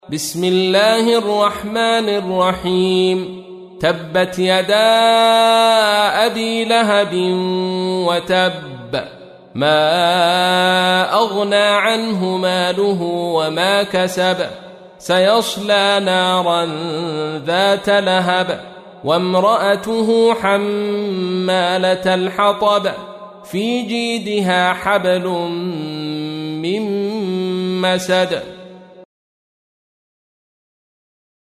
تحميل : 111. سورة المسد / القارئ عبد الرشيد صوفي / القرآن الكريم / موقع يا حسين